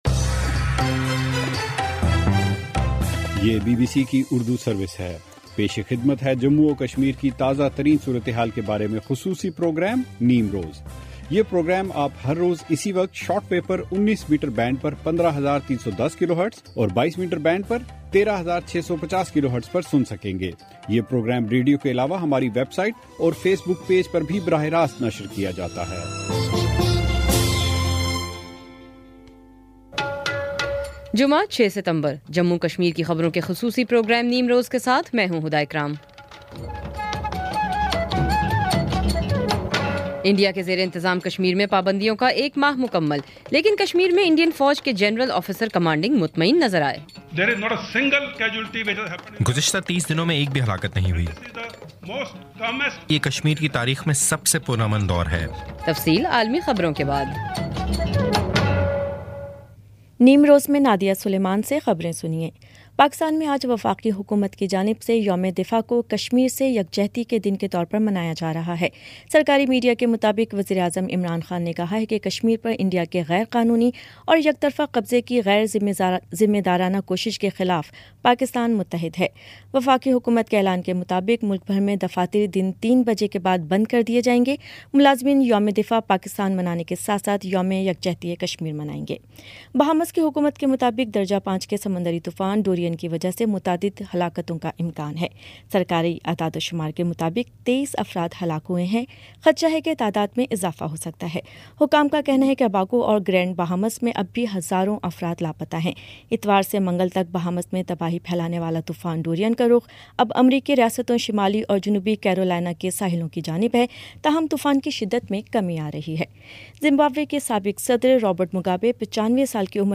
بی بی سی اردو سروس سے جموں اور کشمیر کی خبروں کا خصوصی پروگرام نیم روز